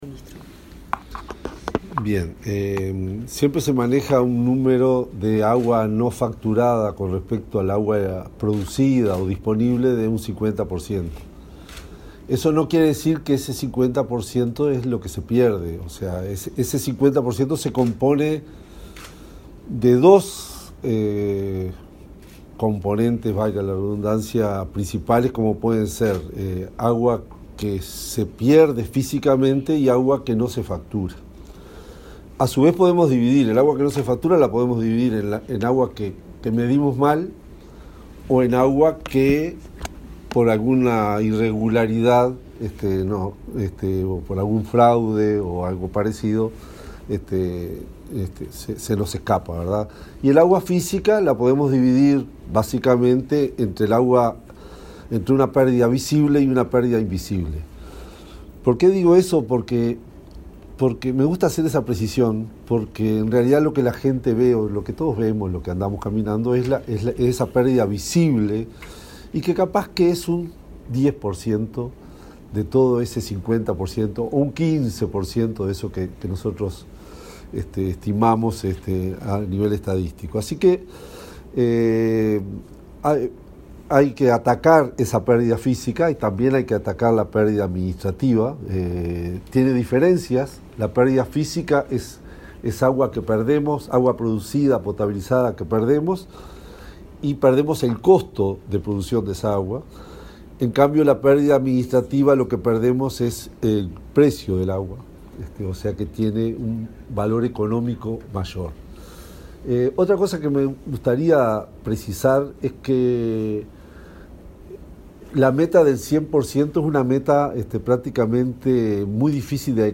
Declaraciones del presidente de OSE, Raúl Montero
Declaraciones del presidente de OSE, Raúl Montero 27/10/2023 Compartir Facebook X Copiar enlace WhatsApp LinkedIn El presidente de OSE, Raúl Montero, y el subsecretario de Ambiente, Gerardo Amarilla, firmaron un convenio de alcance nacional, para elaborar en forma conjunta una estrategia de reducción de agua no contabilizada. Luego, el titular del ente dialogó con la prensa.